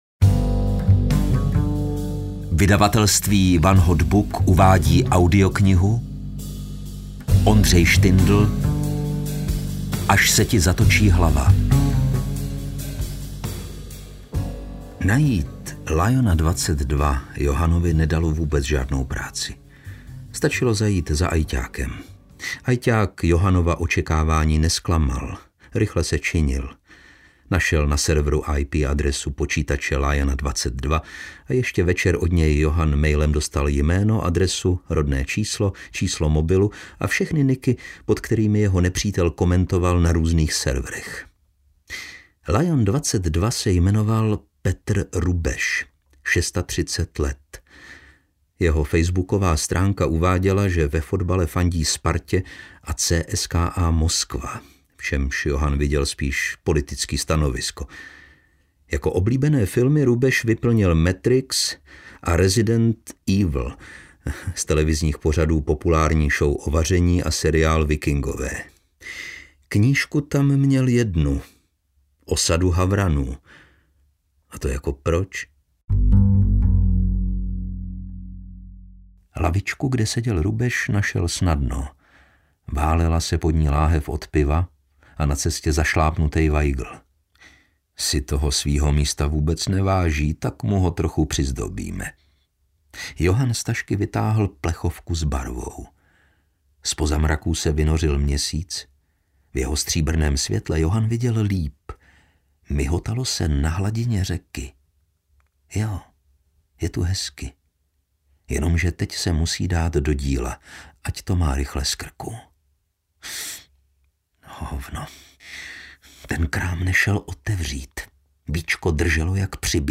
Až se ti zatočí hlava audiokniha
Ukázka z knihy
• InterpretLukáš Hlavica